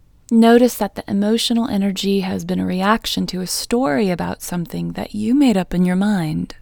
OUT Technique Female English 15